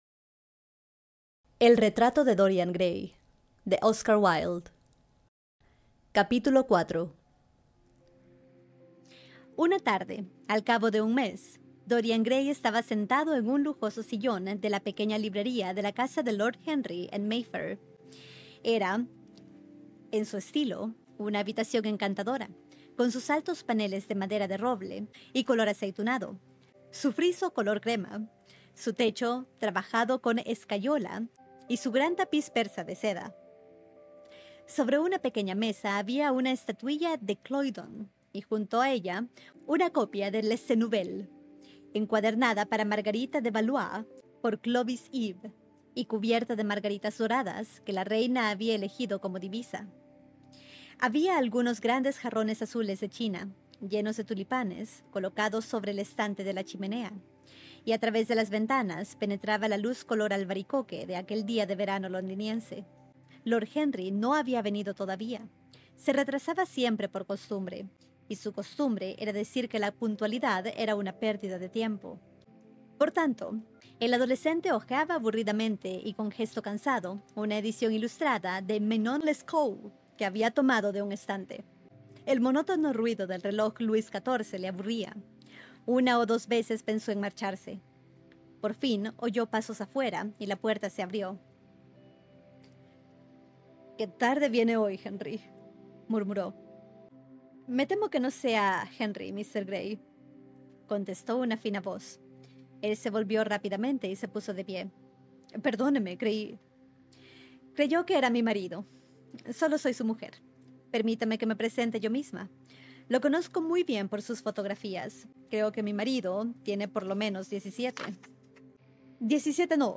En este episodio de Clásicos al Oído, continuamos con la lectura de El Gran Gatsby de F. Scott Fitzgerald, extraída directamente de nuestras sesiones en vivo en Twitch.✨ En este capítulo, conocemos por fin la verdadera historia de Gatsby: su origen humilde, su ambición sin límites y el momento en que decidió reinventarse por completo. Mientras tanto, una nueva visita de Daisy a su mansión sella el contraste entre la ilusión que Gatsby ha construido y el mundo real al que ella pertenece.